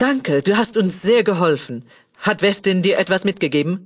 Kategorie:Fallout 2: Audiodialoge Du kannst diese Datei nicht überschreiben.